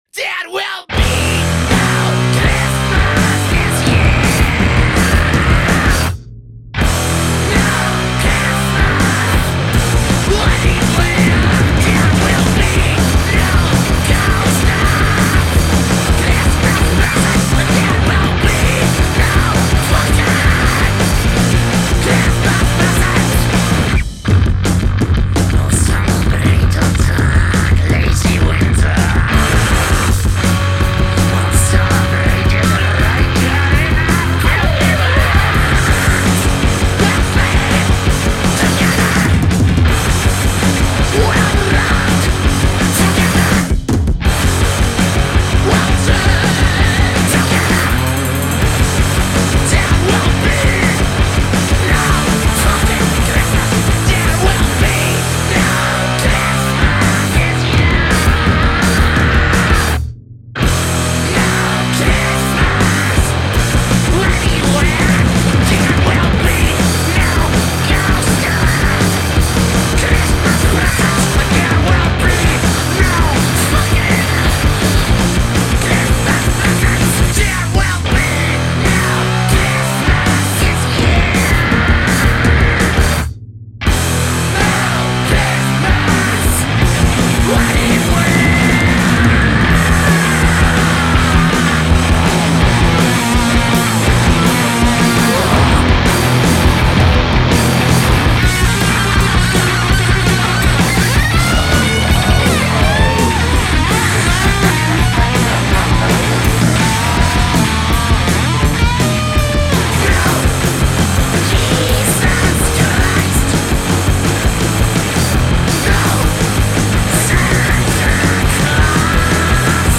Genre: Punk/Hardcore